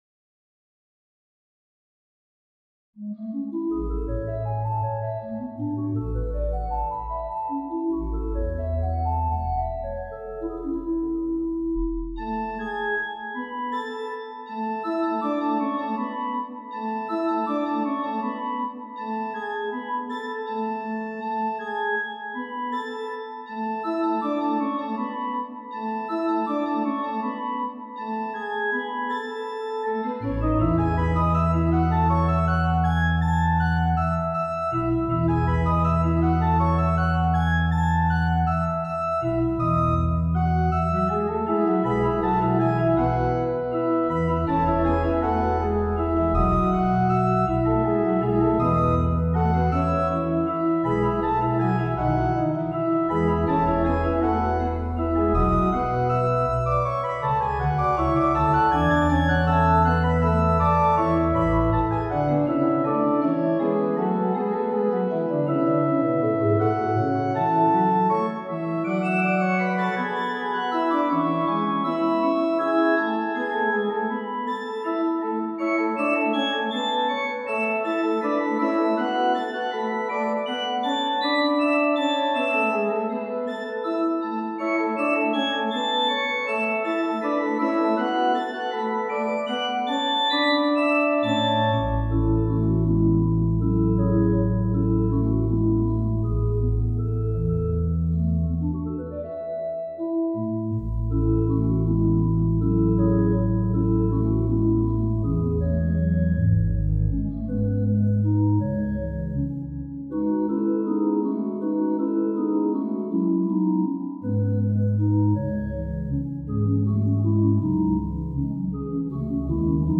for organ After a statement of the tonic and dominant in arpeggios including the seventh, the subject is a jaunty 6/8 and then the subject plays through several related tonal regions.